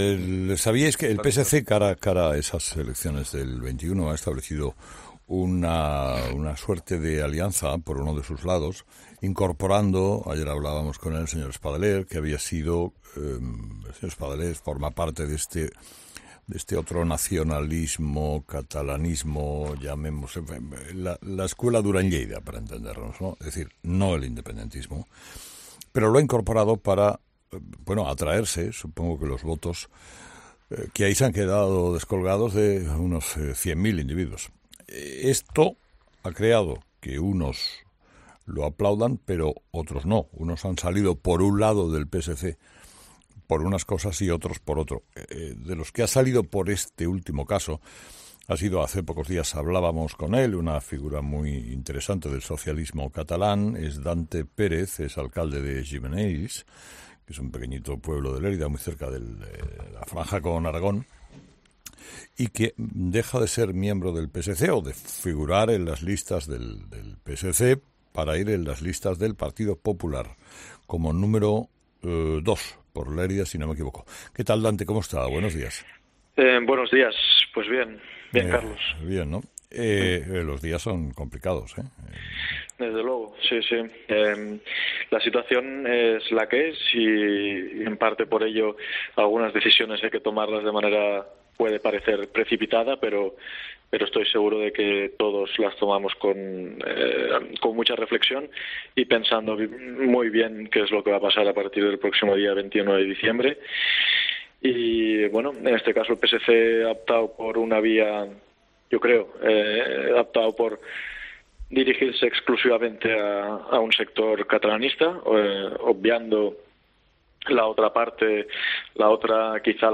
Escucha la entrevista a Dante Pérez, alcalde de Gimenells (Lérida)